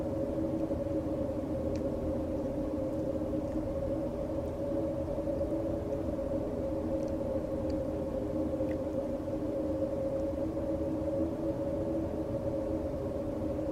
白噪声楼道1.wav